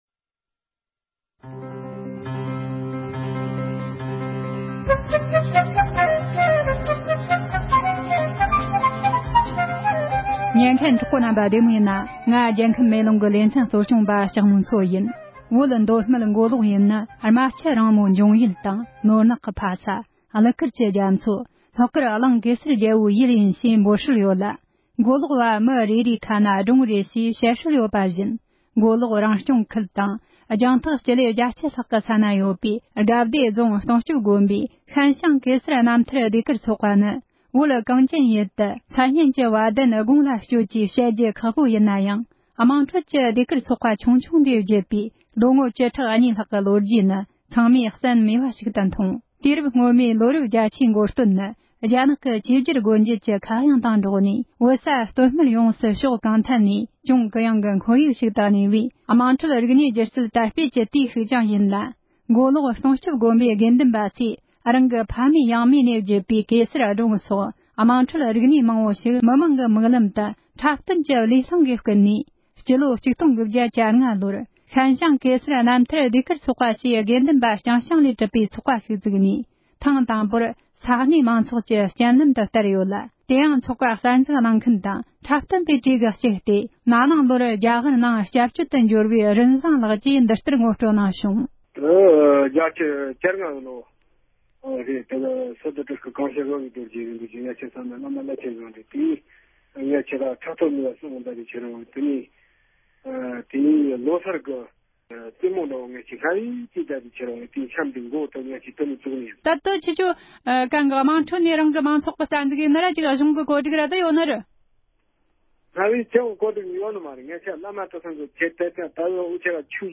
མདོ་སྨད་མགོ་ལོག་དགའ་བདེ་ས་ཁུལ་གྱི་ཤིན་ཤང་རྣམ་ཐར་ཟློས་གར་ཚོགས་པ་དང་འབྲེལ་བའི་ཐད་གླེང་མོལ།